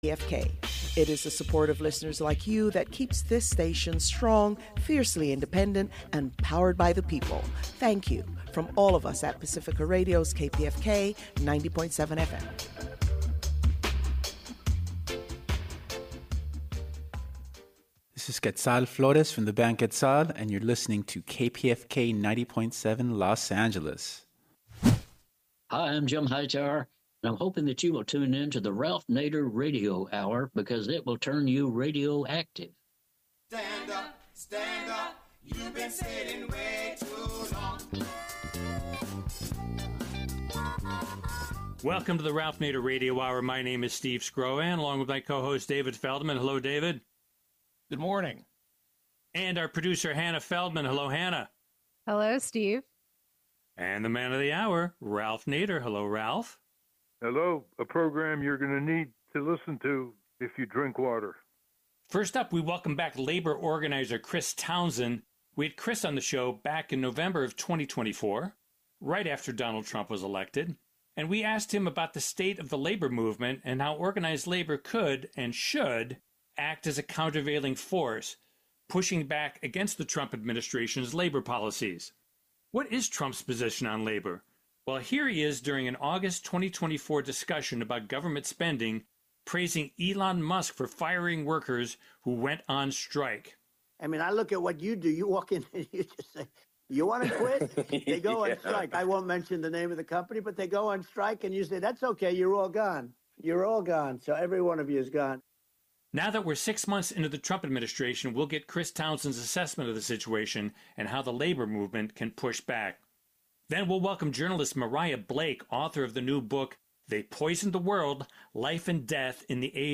Play Rate Listened List Bookmark Get this podcast via API From The Podcast The Ralph Nader Radio Hour is a weekly talk show broadcast on the Pacifica Radio Network.